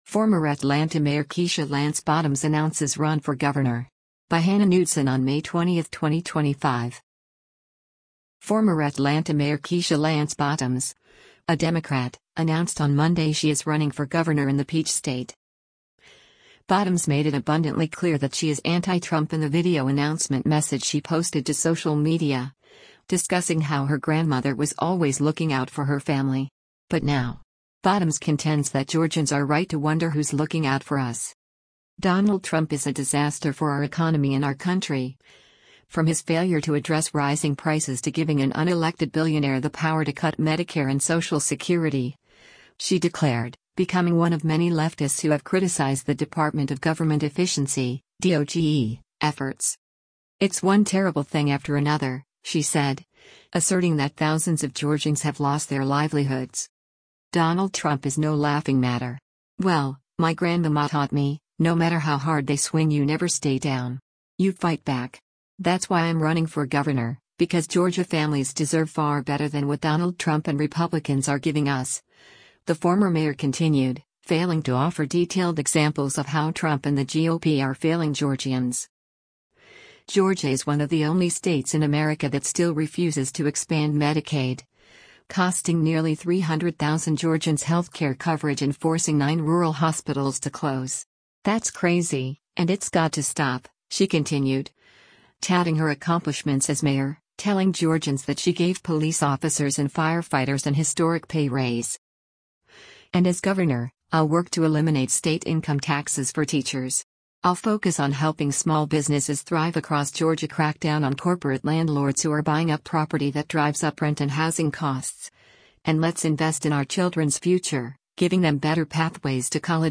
Bottoms made it abundantly clear that she is anti-Trump in the video announcement message she posted to social media, discussing how her grandmother was always “looking out” for her family.